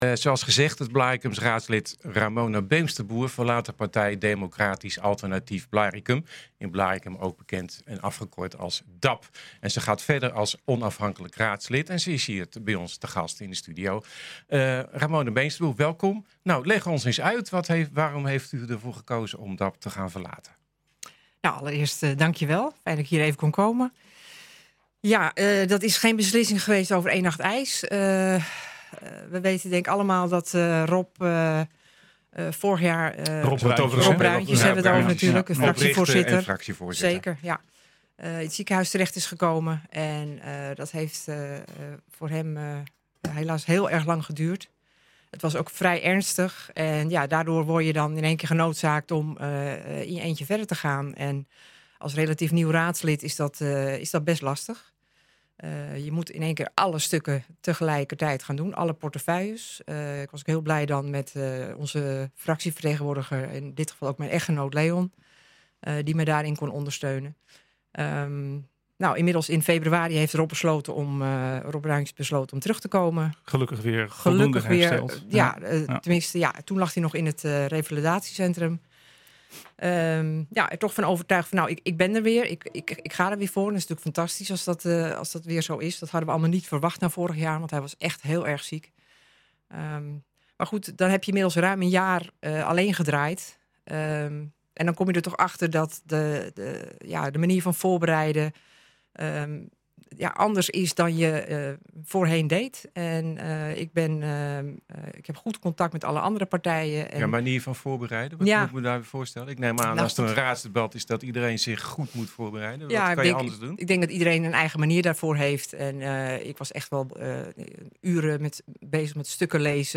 En ze is hier in de studio bij ons.